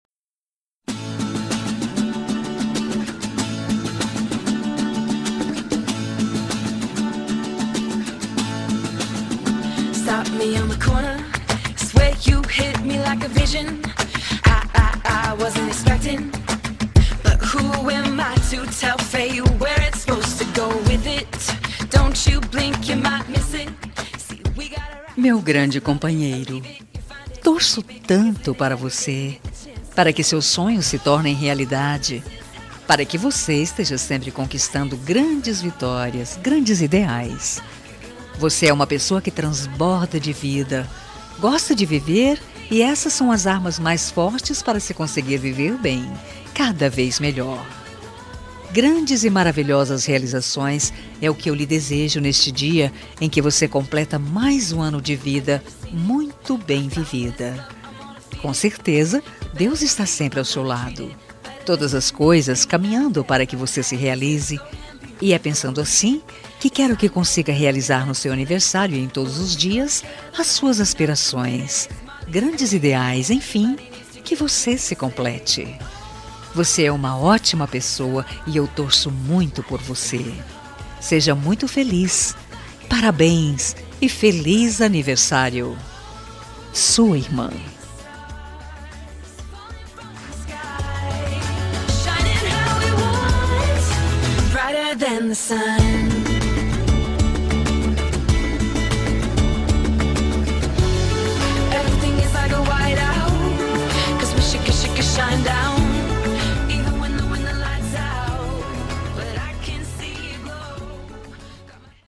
Telemensagem de Aniversário de Irmão – Voz Feminina – Cód: 202231